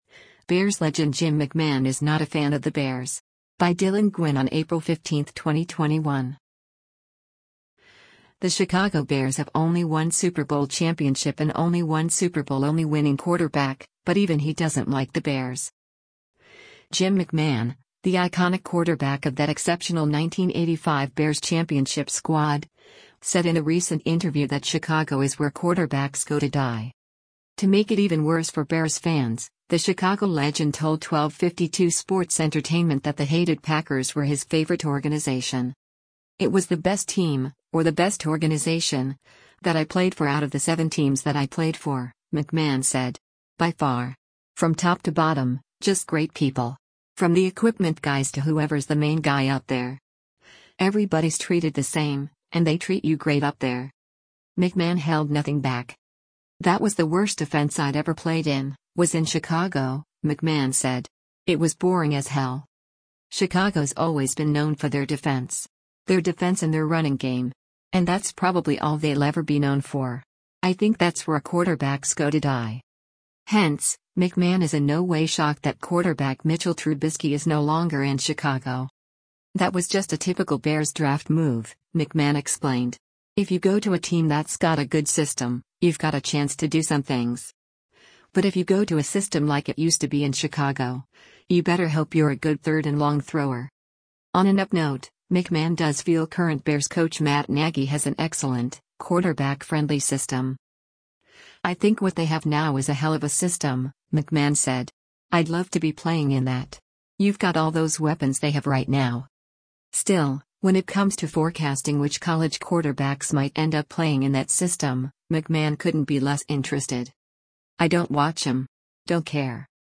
Jim McMahon, the iconic quarterback of that exceptional 1985 Bears championship squad, said in a recent interview that Chicago is “where quarterbacks go to die.”